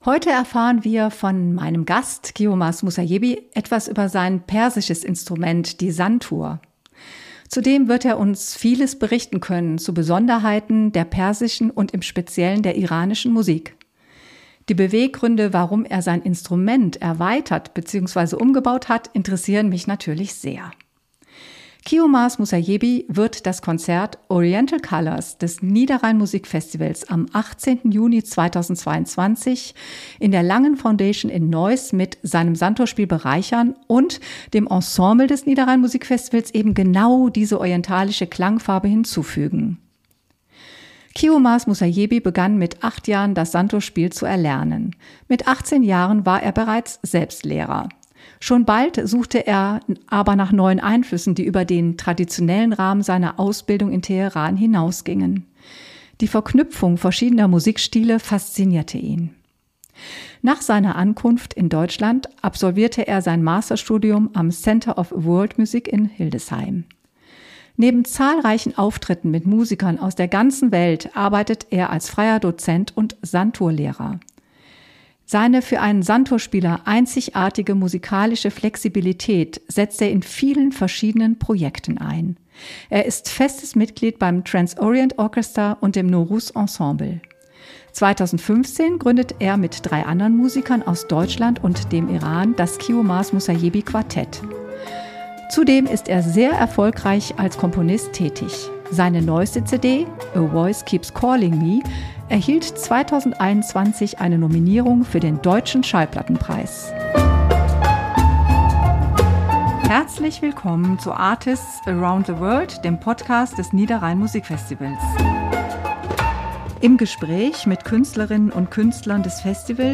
002 Entdeckungsreise zur Santur | Interview